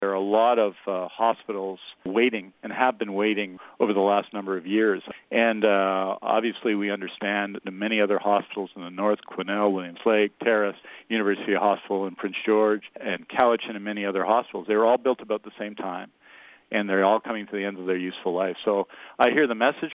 Health Minister Adrian Dix was asked when the time will come for some other hospitals needing updating in the province but didn’t make any promises…..